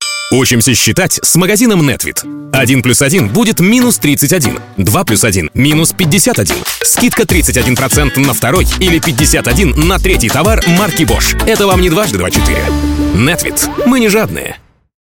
Примеры аудиороликов